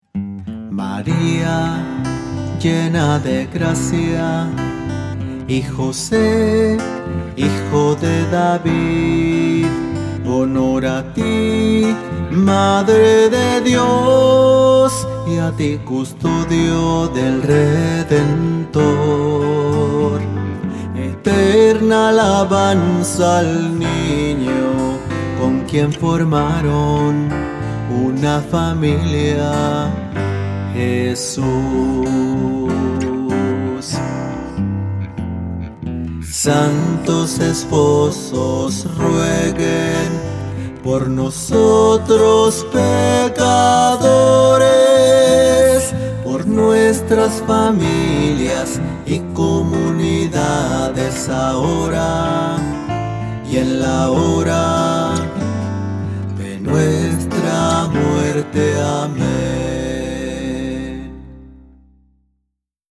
001-Oracion-Cantada.mp3